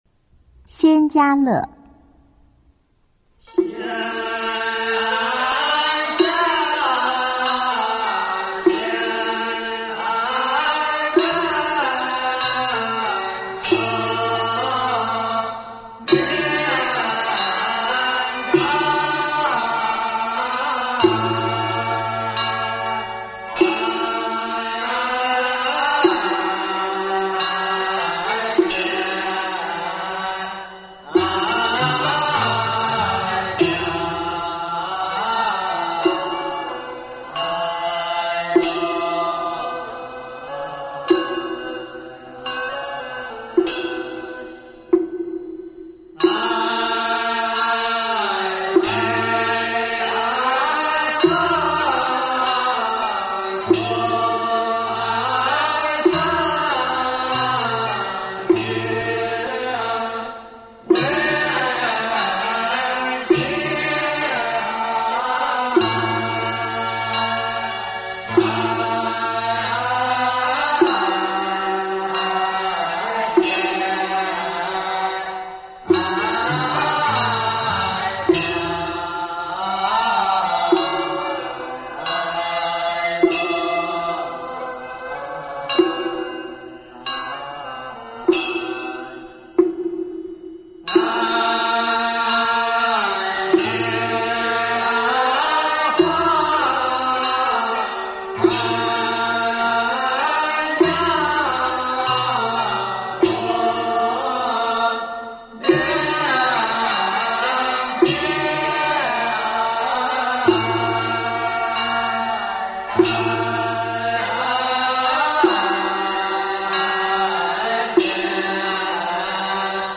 中国道教音乐 全真正韵 仙家乐
简介：仙家乐多用于开经前的诵经、拜忏，以及飞扬欢快的调子，表现出仙人的逍遥自在，尾音中欣慕之情勃然而兴。